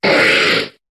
Cri de Vigoroth dans Pokémon HOME.